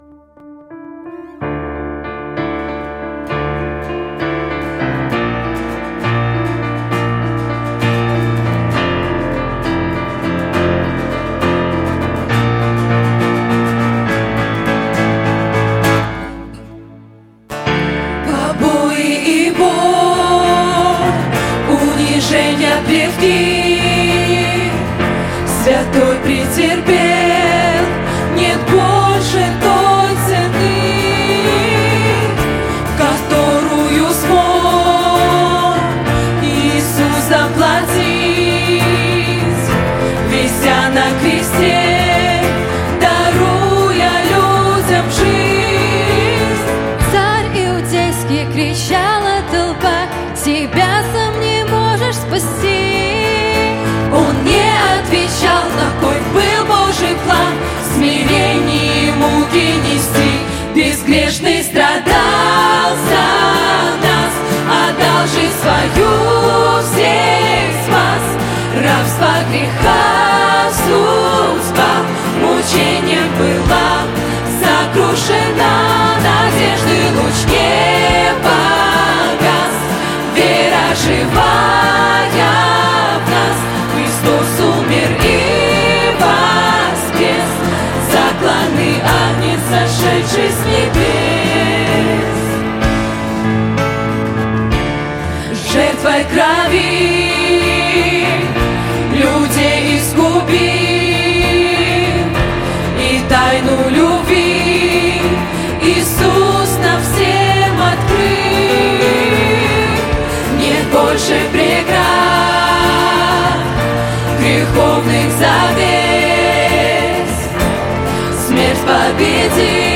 ударные
скрипка
вокал
гитара
клавиши, вокал